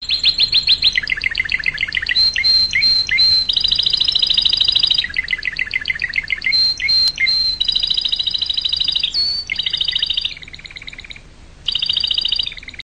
Kanarya sesi ringtones ringtone free download
Animals sounds